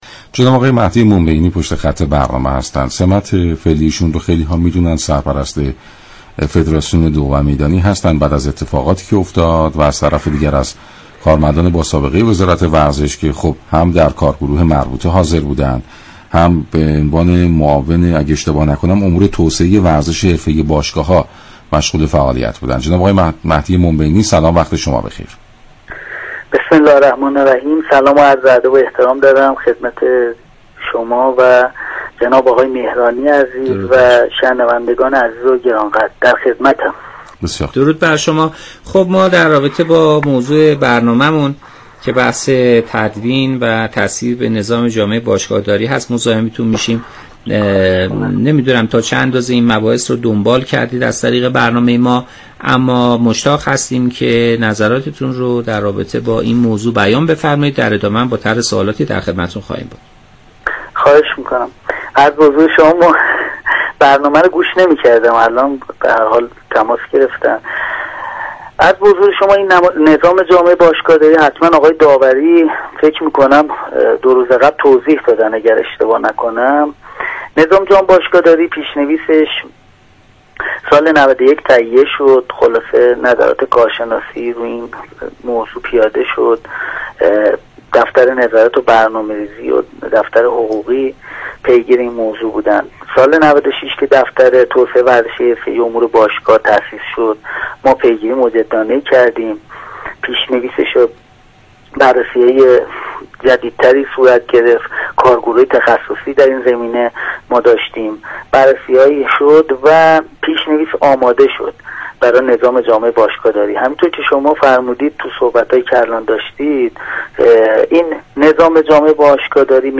مهدی مبینی، سرپرست فدراسیون دوومیدانی و معاون امور توسعه ورزشی باشگاه ها در خصوص طرح نظام جامع باشگاه داری به رادیو ورزش توضیحاتی را ارائه كرد.